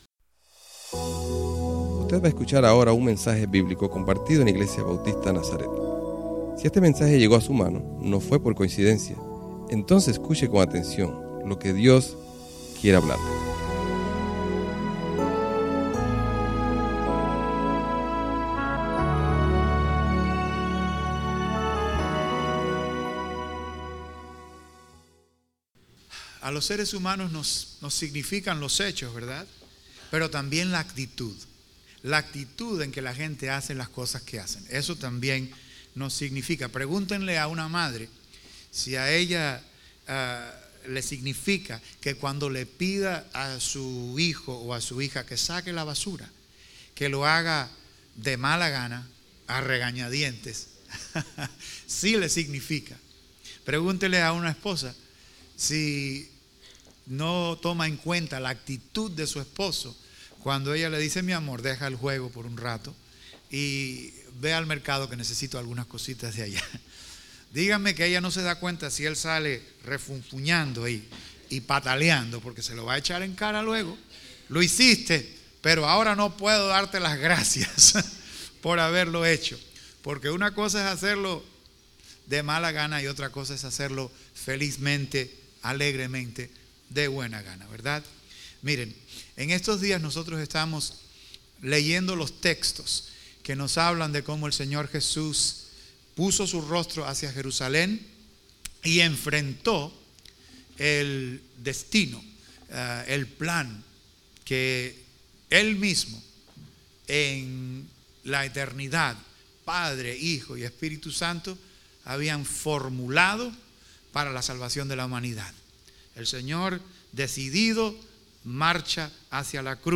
Servicio Dominical